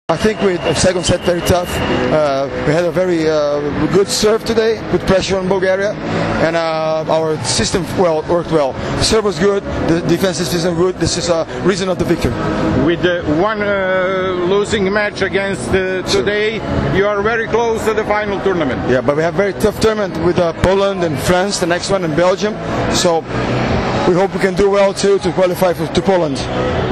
IZJAVA BERNARDA REZENDEA